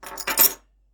【効果音】鍵を置く音 - ポケットサウンド - フリー効果音素材・BGMダウンロード